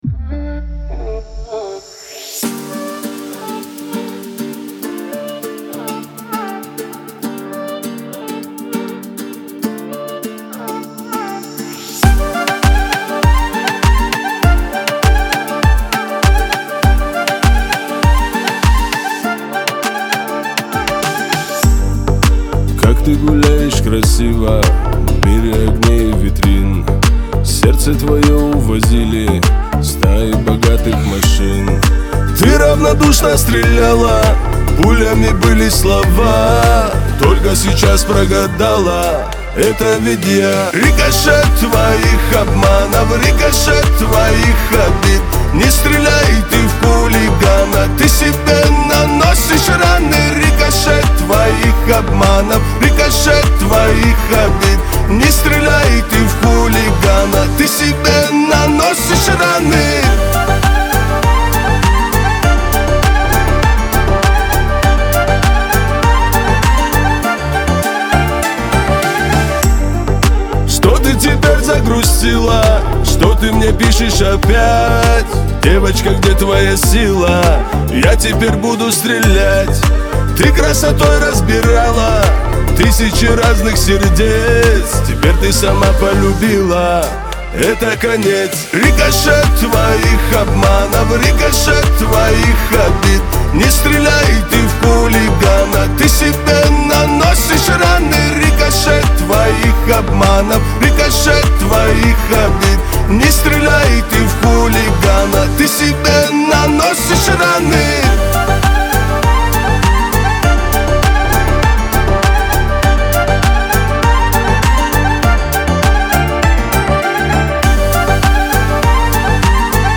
Лирика , грусть , Кавказ – поп